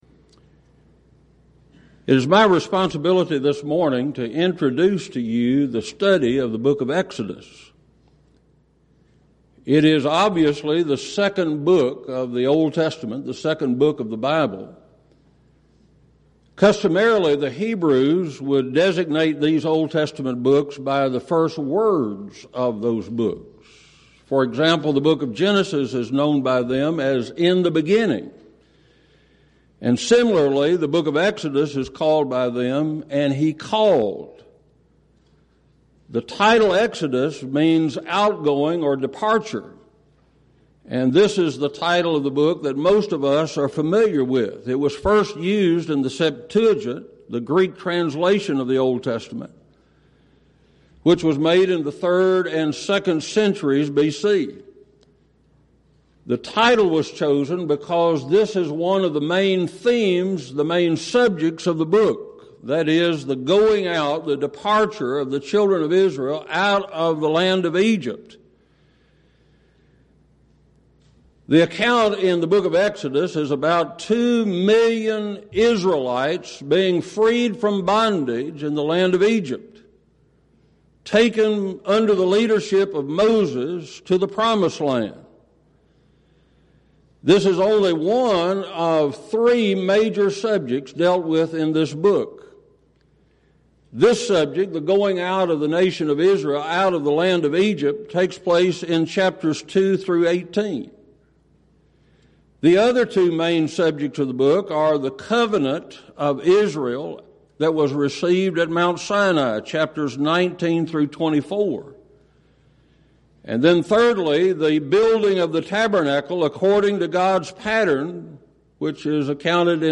Event: 2nd Annual Schertz Lectures